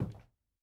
added stepping sounds
Parquet_Floor_Mono_05.wav